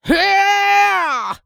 人声采集素材/男2刺客型/CK长声02.wav